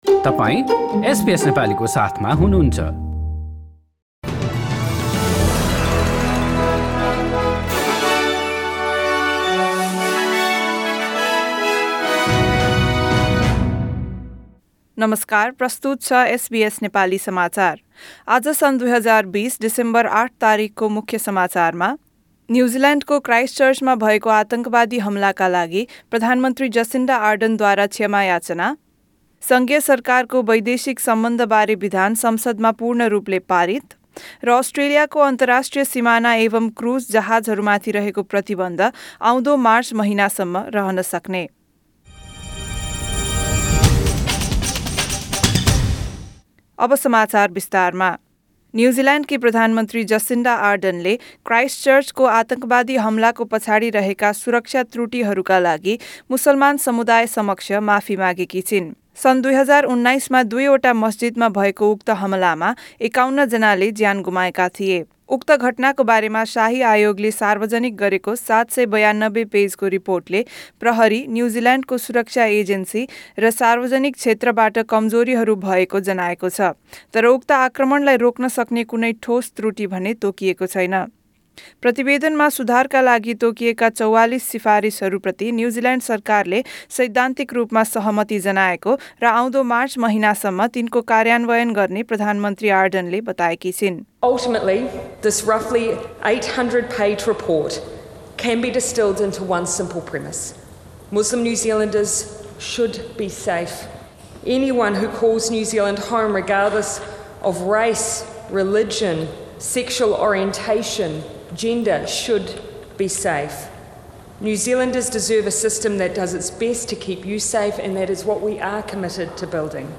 एसबीएस नेपाली अस्ट्रेलिया समाचार: मङ्गलबार ८ डिसेम्बर २०२०